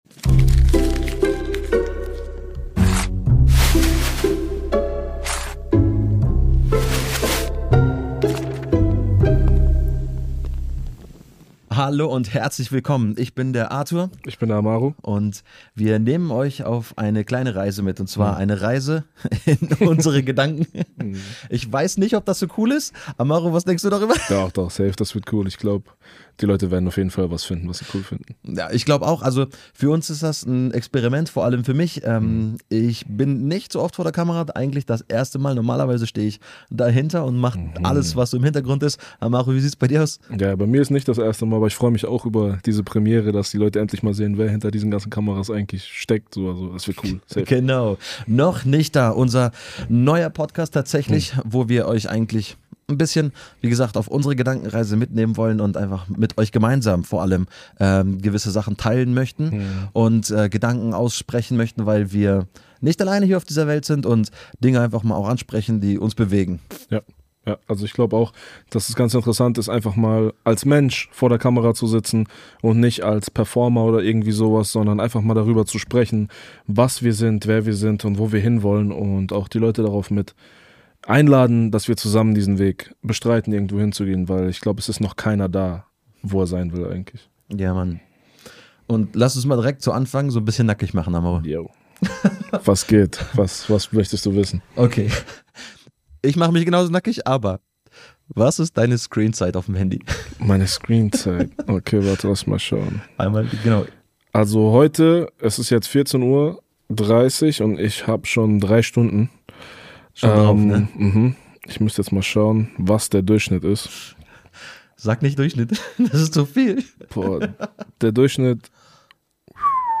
Zwei Kumpels, ehrliche Gespräche, echte Fragen – und eine Menge Inspiration fürs Leben.